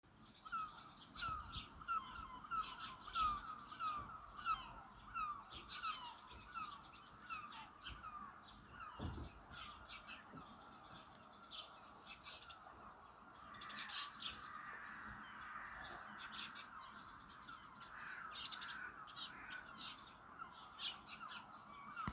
Mājas zvirbulis, Passer domesticus
Administratīvā teritorijaRīga